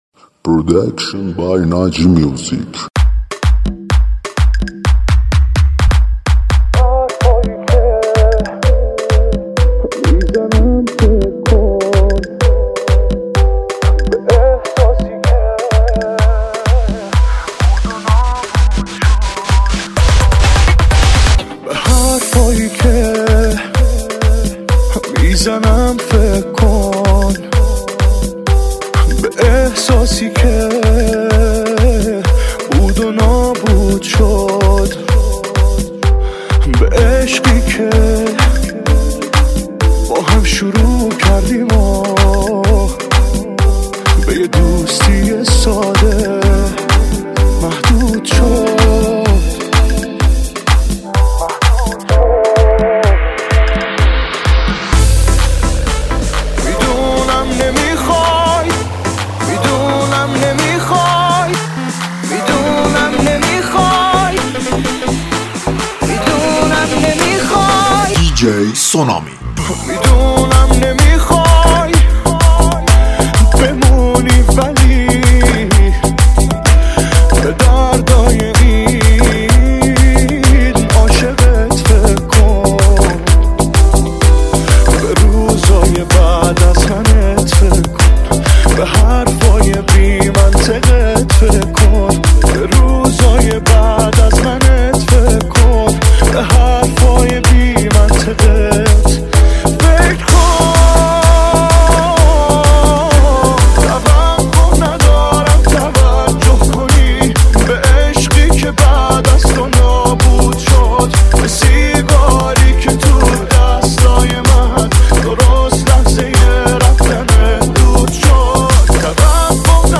آهنگ شاد تریبال مخصوص پارتی و رقص